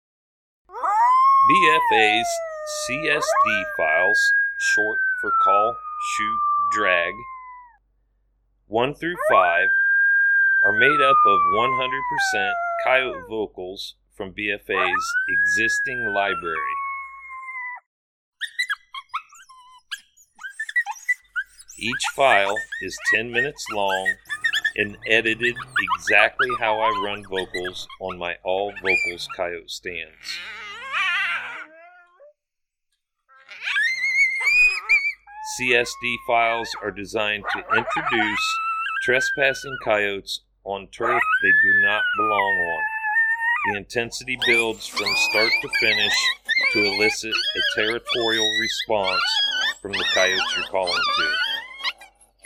Each BFA CSD File is 10 minutes in length, made up from our most popular Coyote Howls, Coyote Social Vocalizations and Coyote fights.